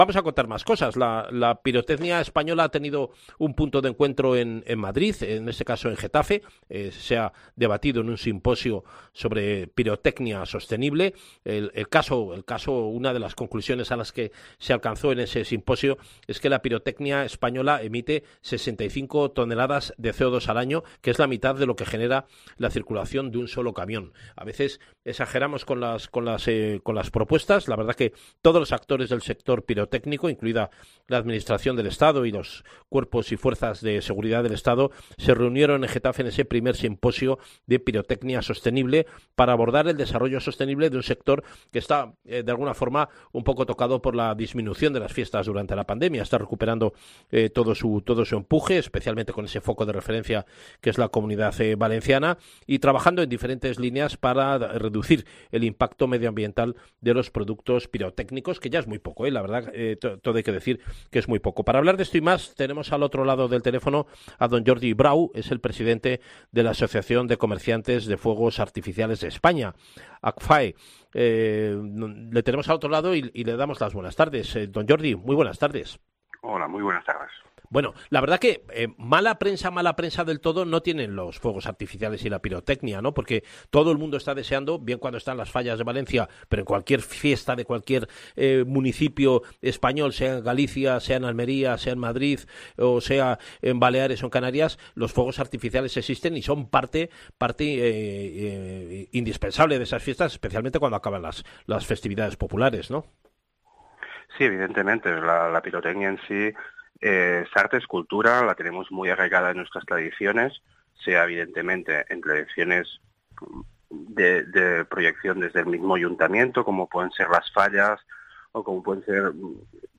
Puedes escuchar aquí la entrevista completa